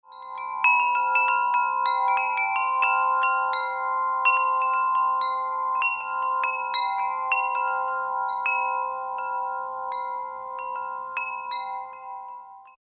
Woodstock Chimes, Bells and Gongs